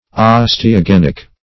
Osteogenic \Os`te*o*gen"ic\, a.
osteogenic.mp3